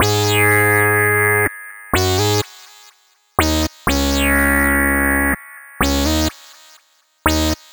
Bleep Hop Lead.wav